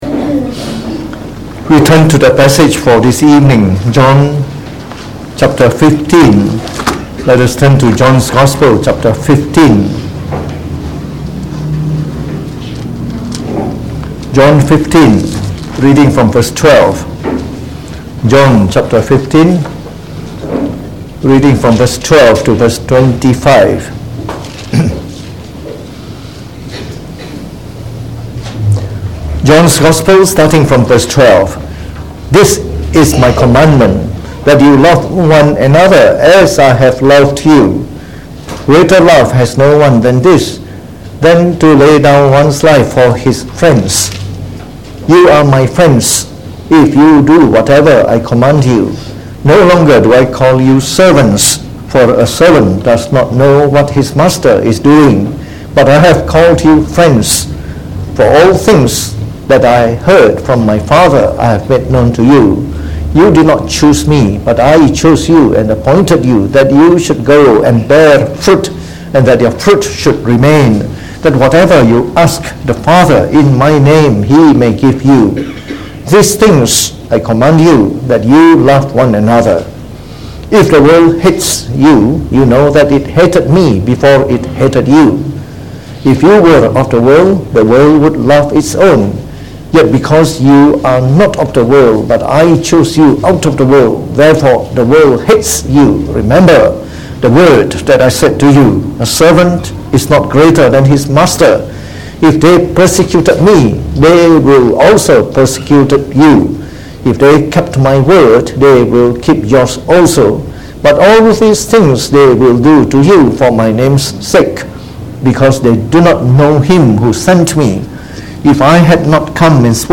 Preached on the 5th May 2019.